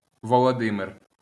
Volodymyr (Ukrainian: Володимир, IPA: [woloˈdɪmɪr]